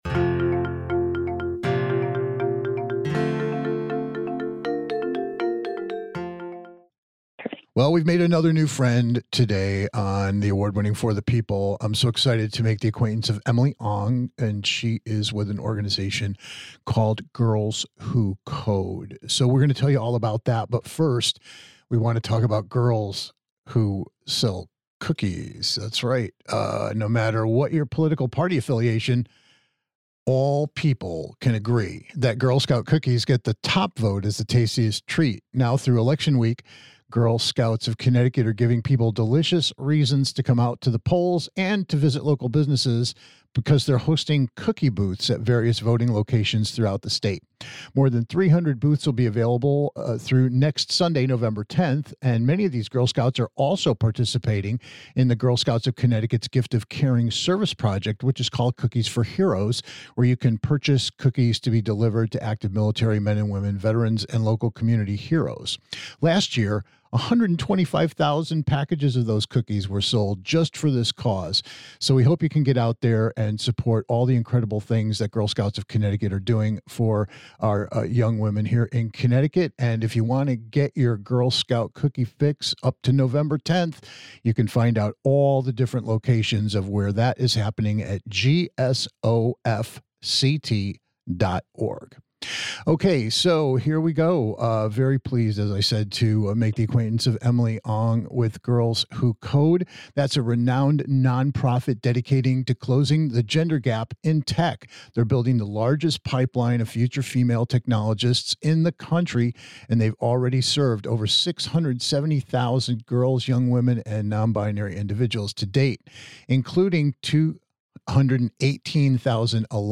Check out our spirited conversation with Girls Who Code - an organization committed to inspiring young women and nonbinary students to step up, learn, and explore possible career opportunities in the STEM arena by learning how to code.